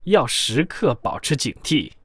文件 文件历史 文件用途 全域文件用途 Kg_fw_02.ogg （Ogg Vorbis声音文件，长度2.1秒，109 kbps，文件大小：27 KB） 源地址:地下城与勇士游戏语音 文件历史 点击某个日期/时间查看对应时刻的文件。 日期/时间 缩略图 大小 用户 备注 当前 2018年5月13日 (日) 02:12 2.1秒 （27 KB） 地下城与勇士  （ 留言 | 贡献 ） 分类:卡坤 分类:地下城与勇士 源地址:地下城与勇士游戏语音 您不可以覆盖此文件。